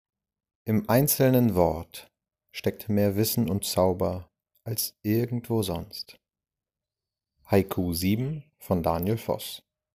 Haiku
Rezitation: